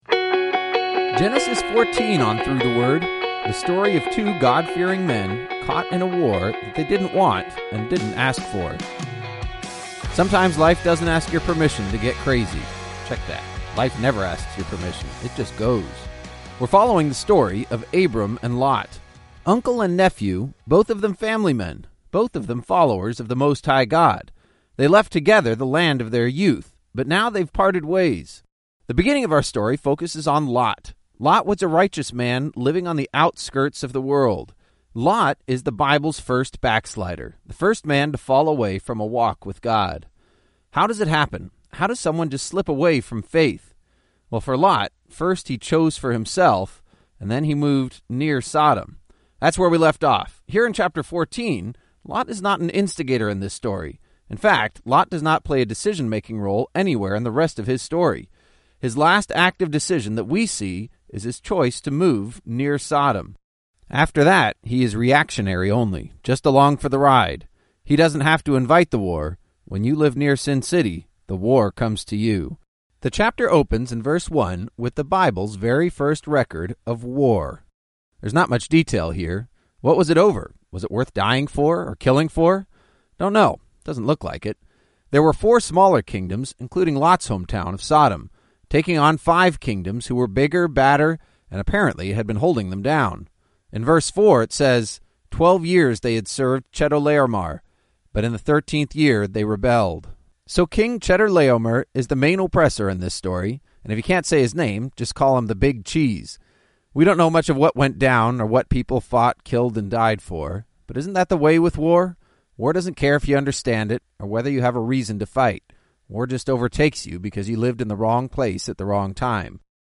19 Journeys is a daily audio guide to the entire Bible, one chapter at a time.